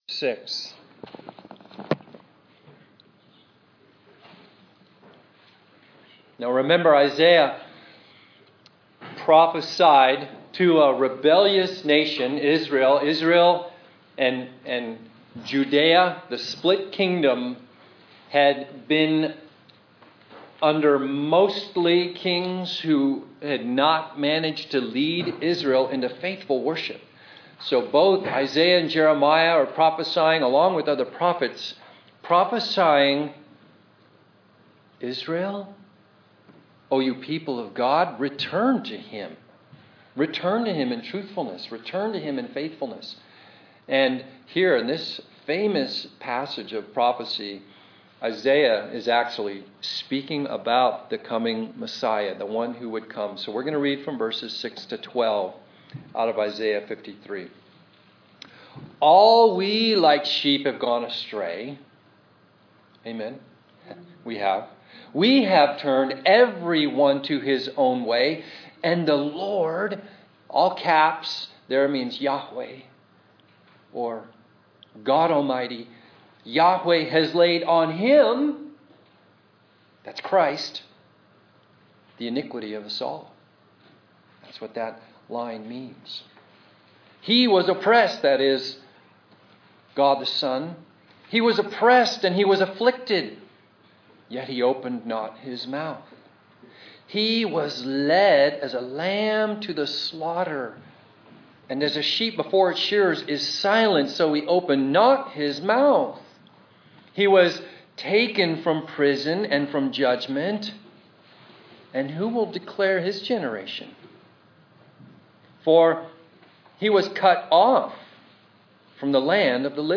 The Dome Church In Laytonville
Sermon